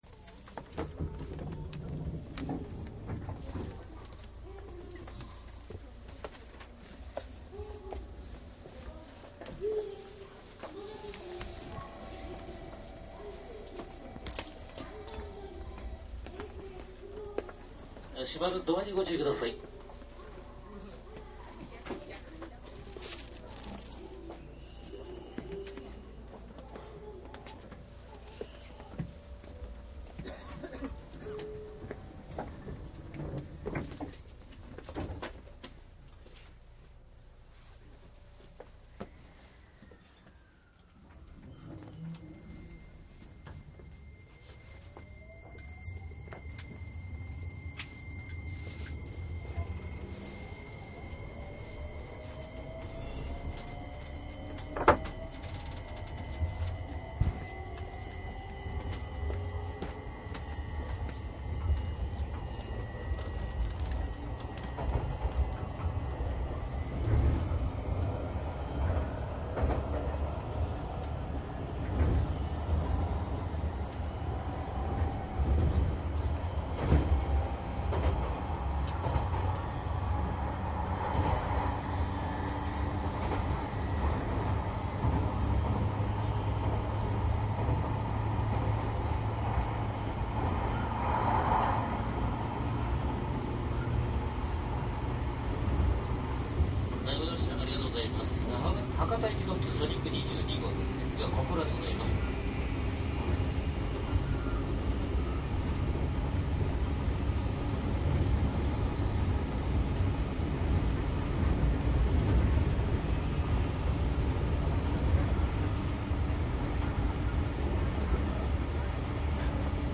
♪JR九州 日豊線上り 885系 ソニック走行音 ＣＤ ♪ 日豊線 上りで885系ソニックを録音したCDです。大分から行橋までソニック10号で録音しています。
DATかMDの通常SPモードで録音（マイクＥＣＭ959）で、これを編集ソフトでＣＤに焼いたものです。